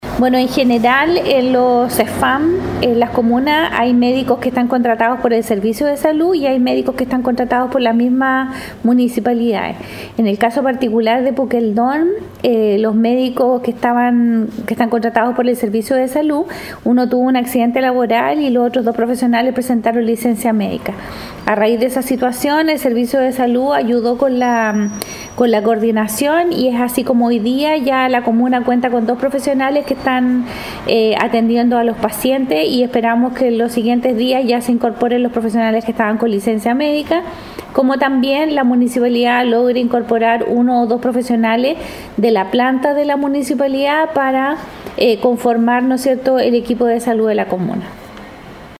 Al respecto, la directora del Servicio de Salud Chiloé, Marcela Cárcamo, explicó que en la comuna existen médicos contratados tanto por el Servicio.